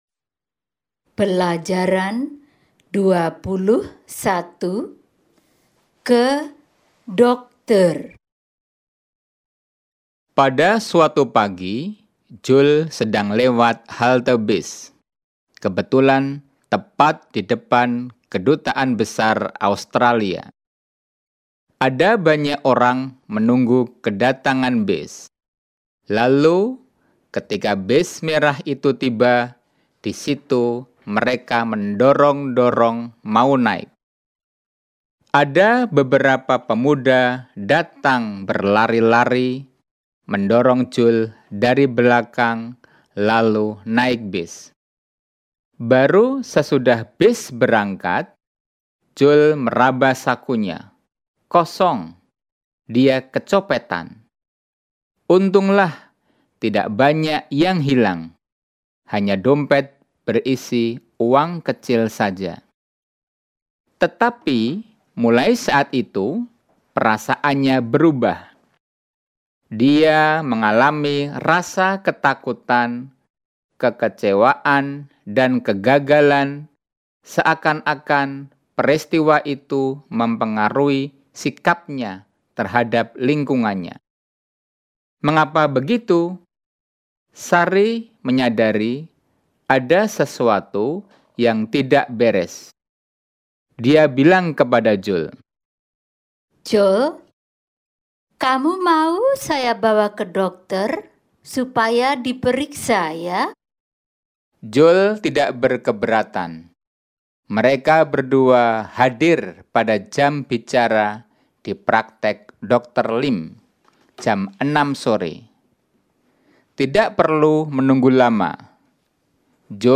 Dialogue 21 Going To The Doctor